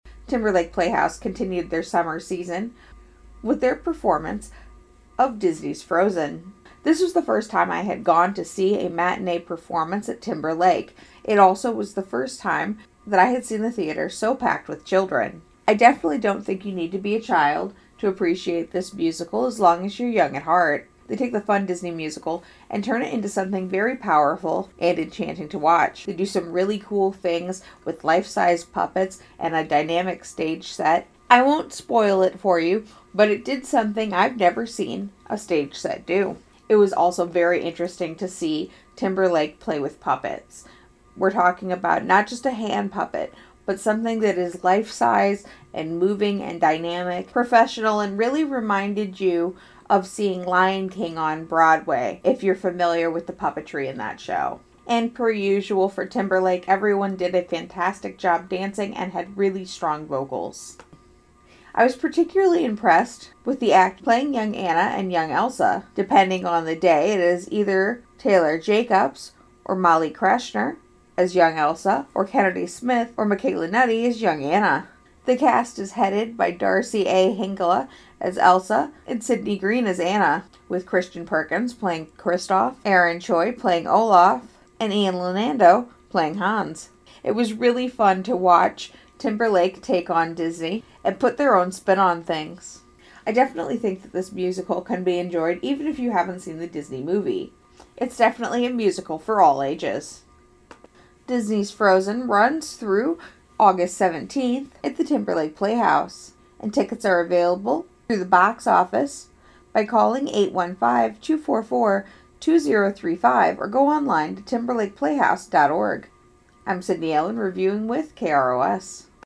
KROS Review of Frozen at TLP
Frozen Review